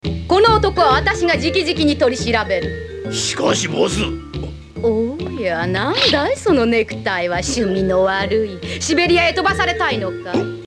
Audio file in Japanese. Boss Lady, performed by You Inoue